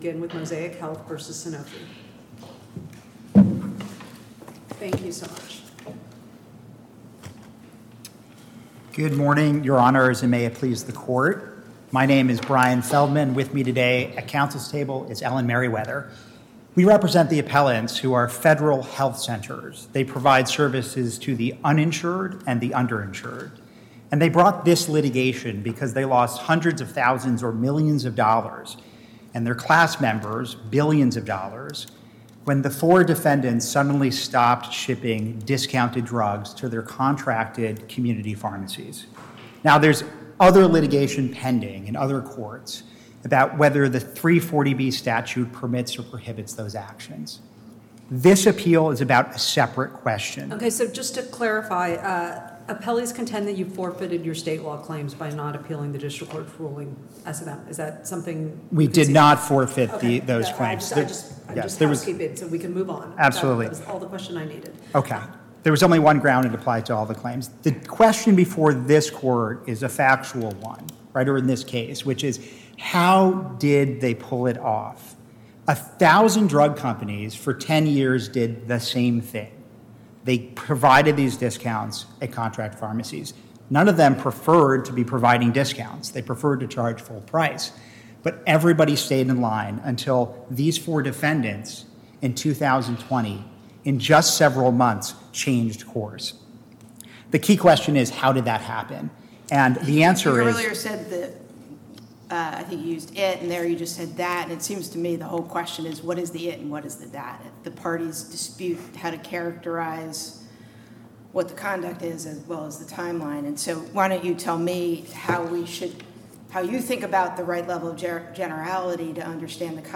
Oral argument audio Share this: Email Print Facebook LinkedIn Twitter